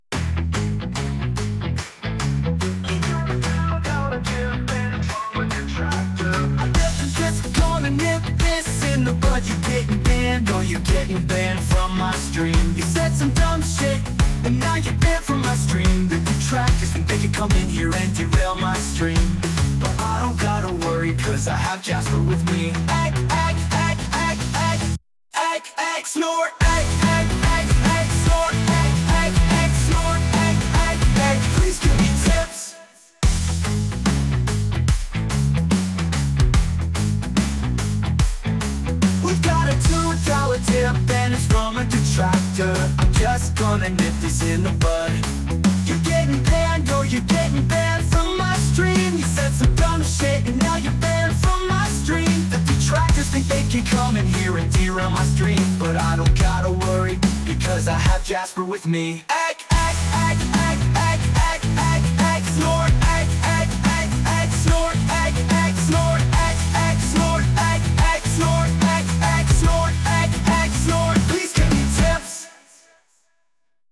country song